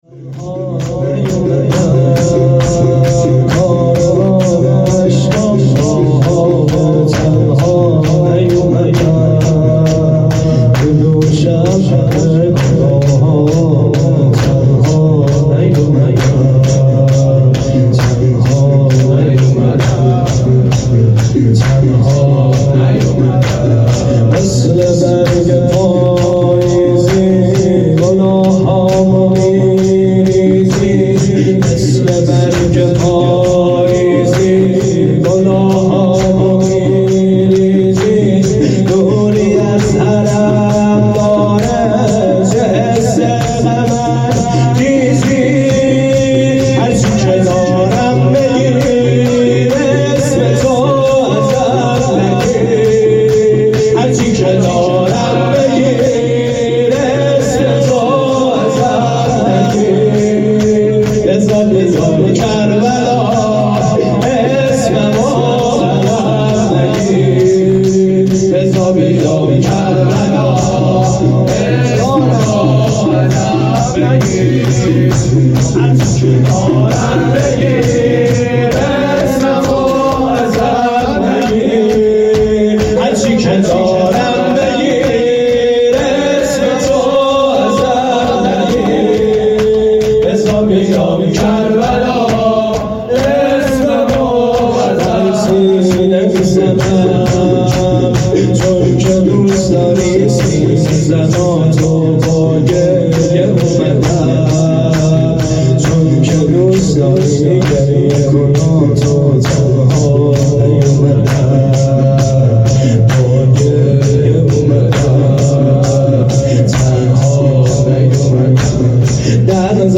بخش هفتم شور
شب تاسوعا محرم الحرام 1443 | هیئت کاروان حسینی (ع) | ۲۶ مرداد 1400